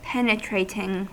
Uttal
Synonymer keen pervasive Uttal US Ordet hittades på dessa språk: engelska Ingen översättning hittades i den valda målspråket.